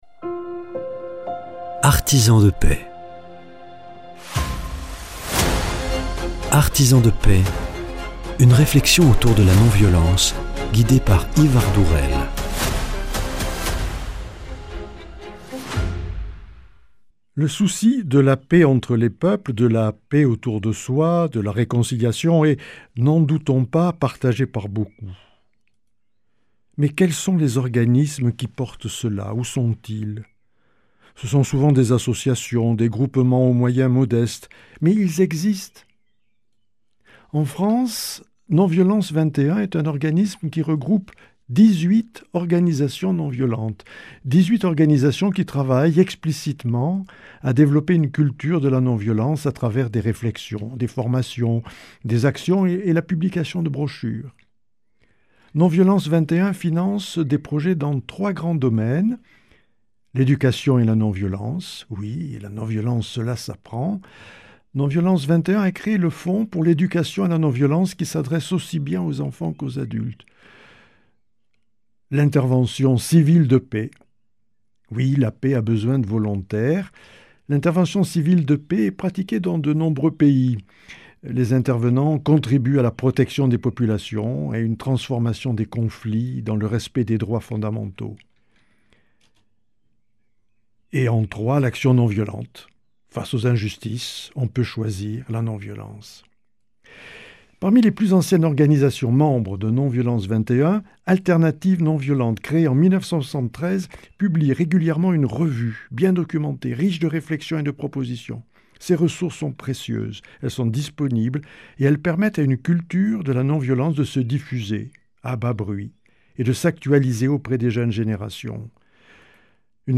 Non violence XXI, un organisme pour l’éducation, la publication de brochures et l’action. Quatre étudiantes infirmières s’engagent pour le soin et témoignent.